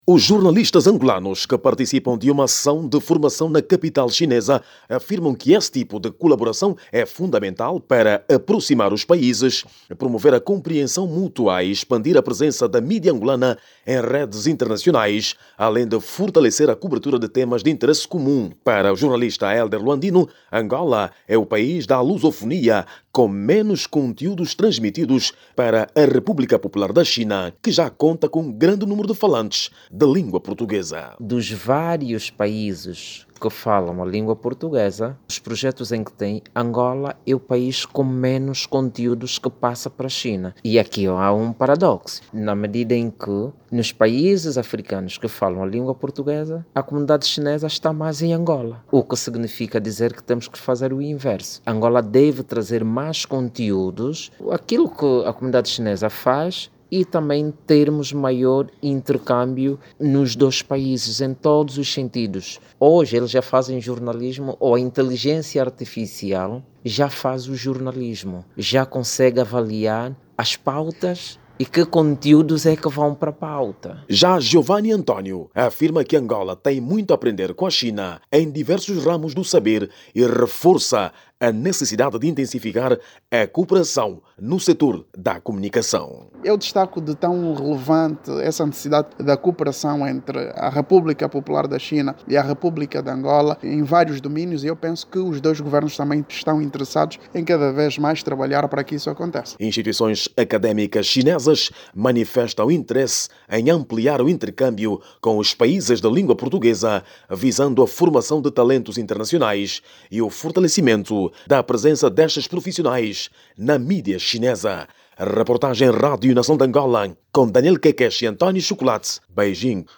Os Jornalistas Angolanos querem maior intercâmbio com a China em diversas áreas com especial ênfase no sector da comunicação social. O que se pretende é aprofundar o conhecimento, trocar experiências e fortalecer mais a cooperação mediática entre os dois países. Ouça no áudio abaixo toda informação com a reportagem